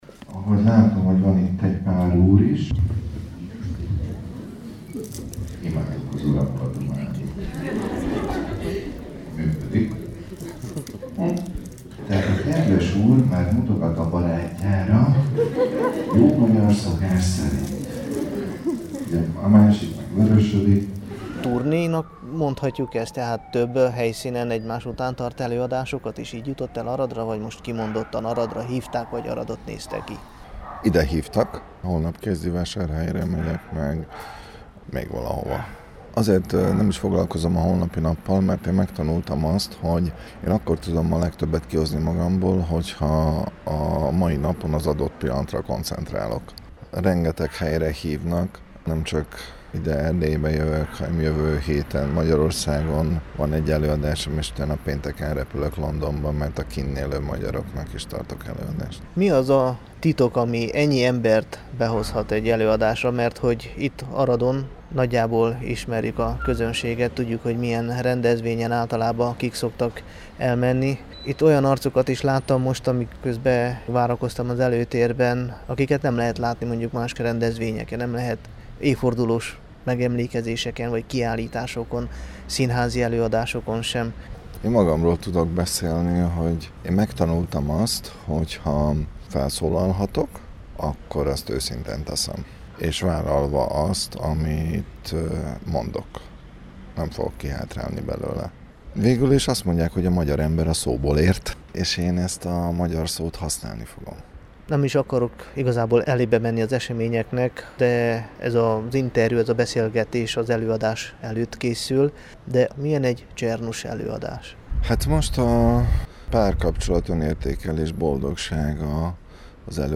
csernus_doki_aradon.mp3